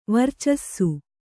♪ varcassu